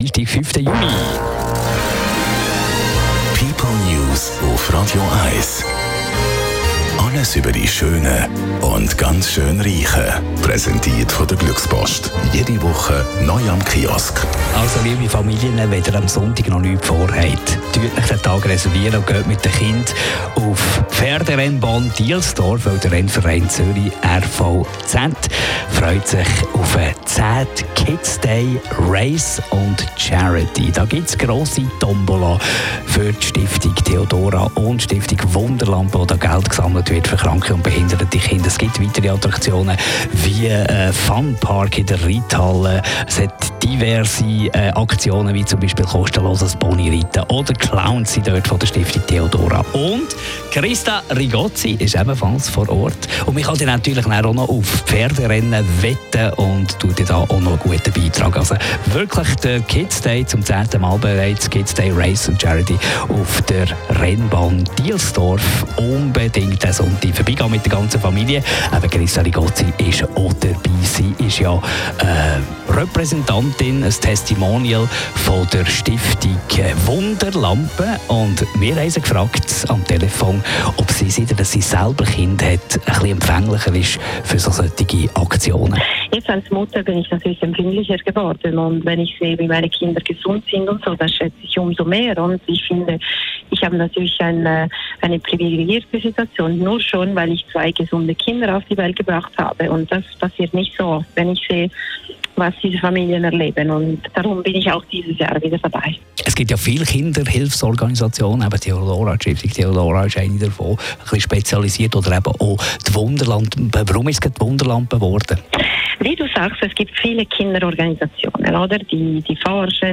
Radio 1 Interview mit Christa Rigozzi